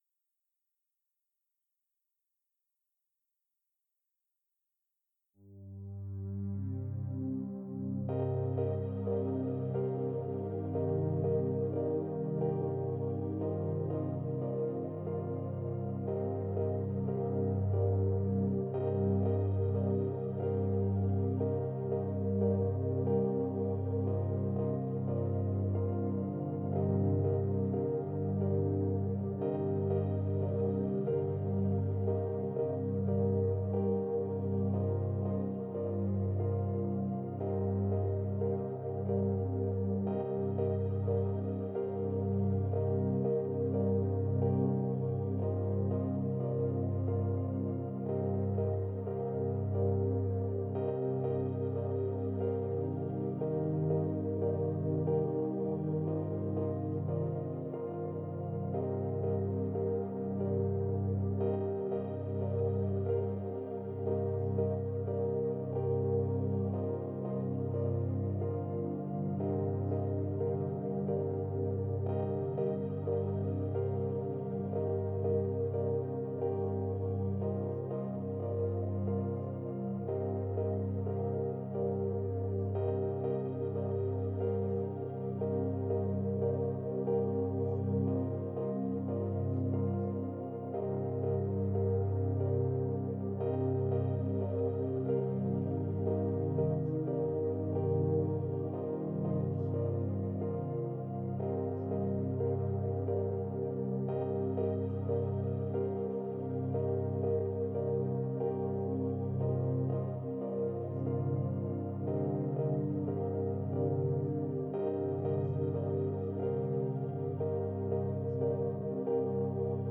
Category: Meditative
Contains a positive uplifting silent affirmation.